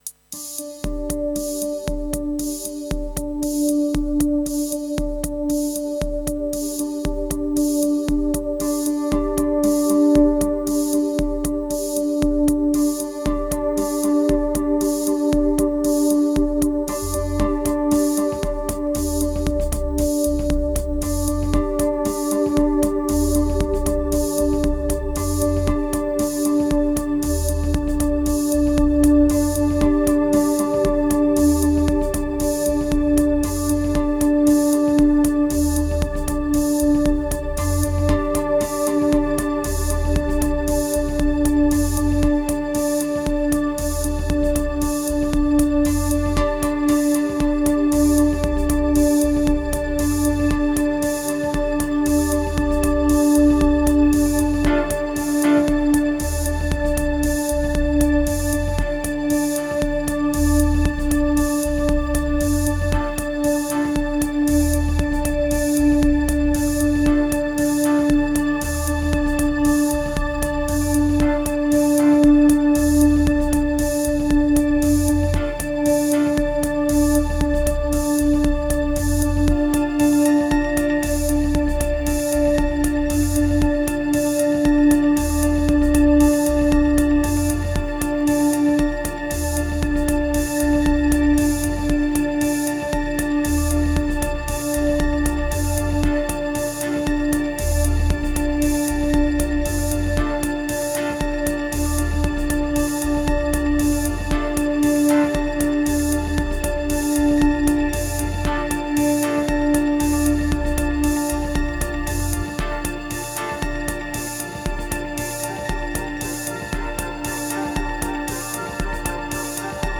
Further downwards, aiming for ambient, ends up
2172📈 - 95%🤔 - 58BPM🔊 - 2023-09-04📅 - 1250🌟